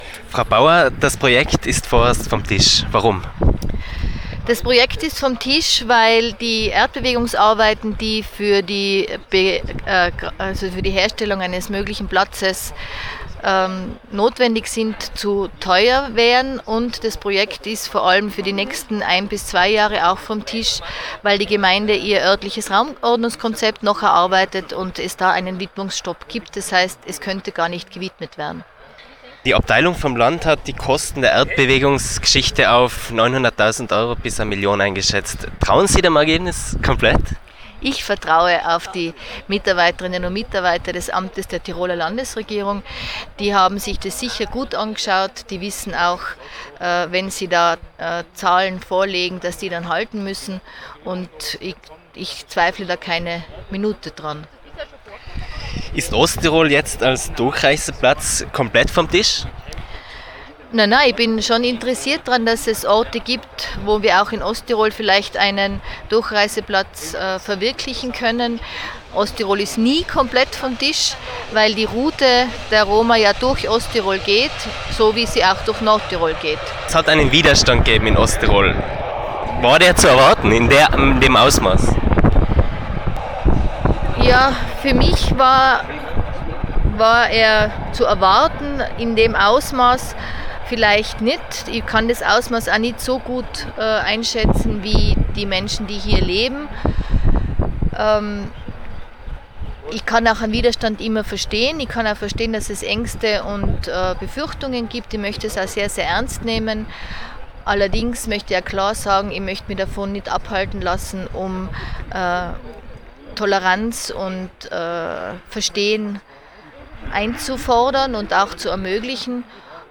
Die Landesrätin im Originalton:
Interview-Baur.mp3